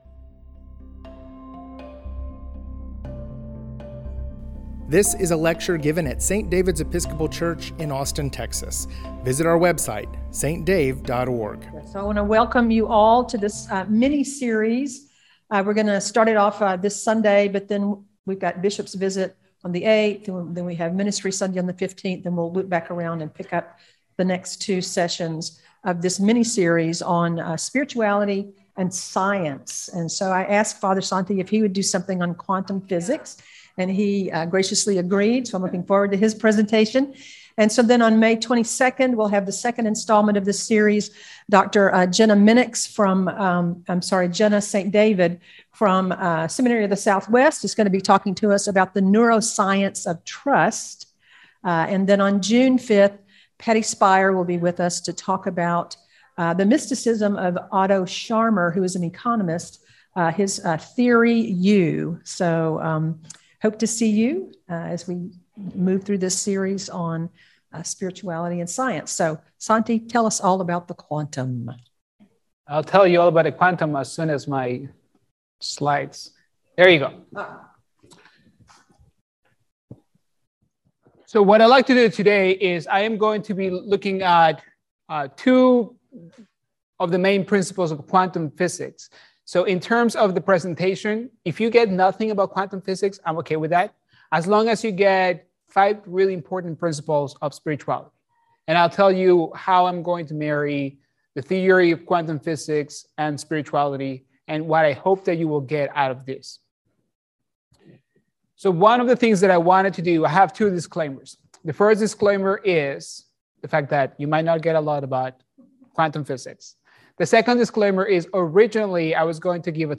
Spirituality and Science Lecture Series: Quantum Physics